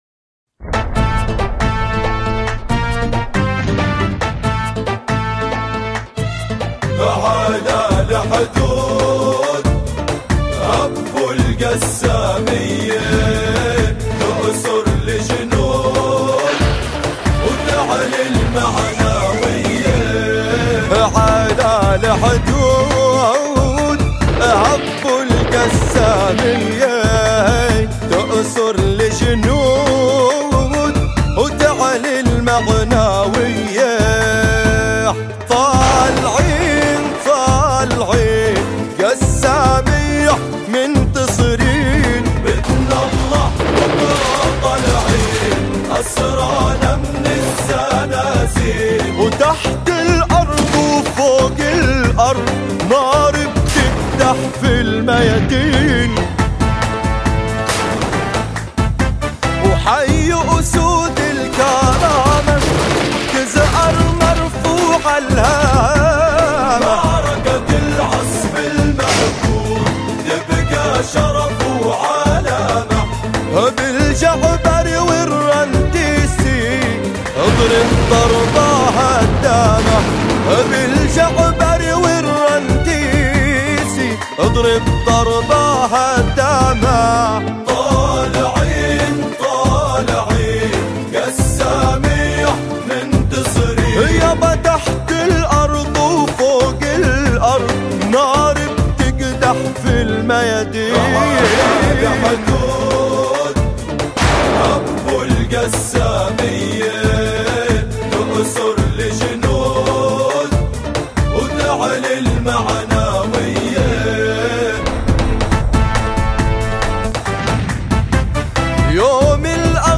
أناشيد فلسطينية... على الحدود